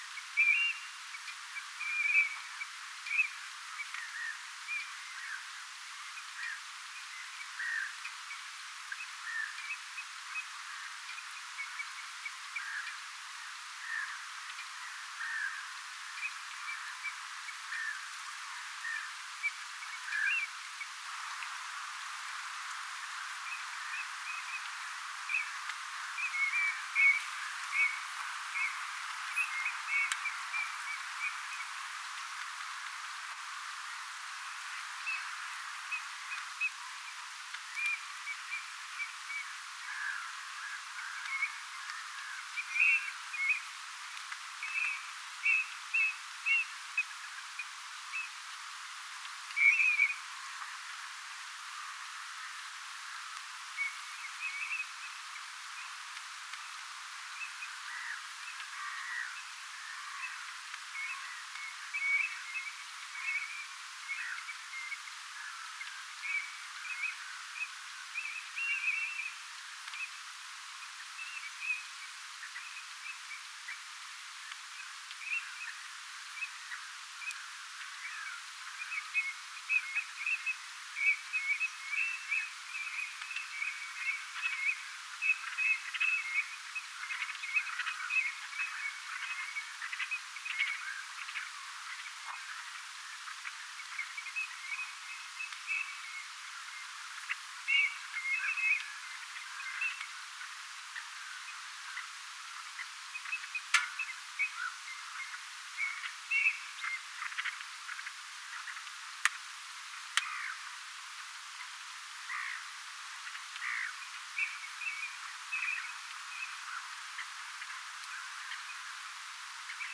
hienolta kuulostavaa tavien soidinta